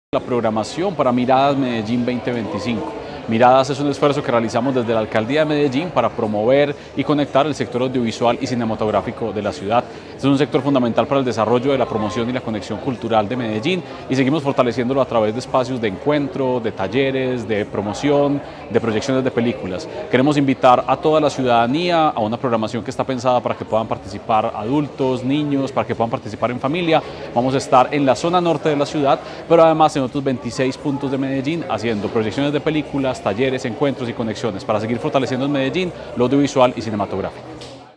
Declaraciones-secretario-de-Cultura-Ciudadana-Santiago-Silva-Jaramillo-1.mp3